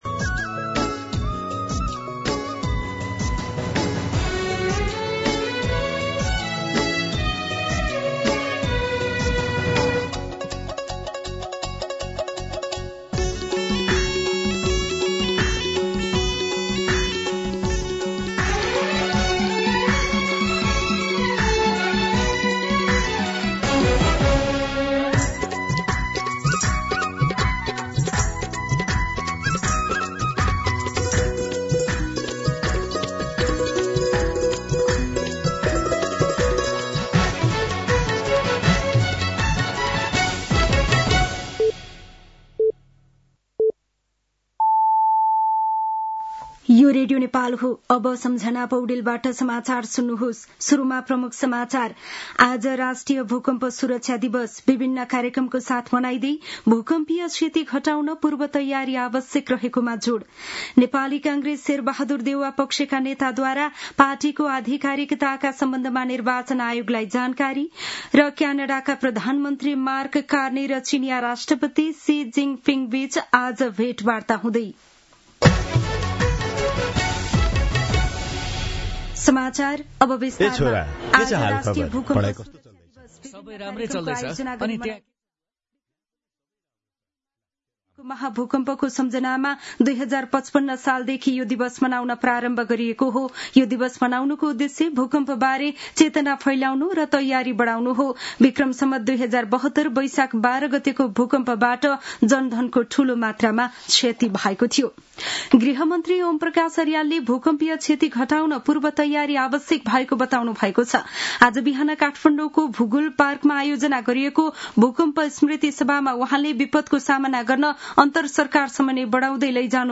दिउँसो ३ बजेको नेपाली समाचार : २ माघ , २०८२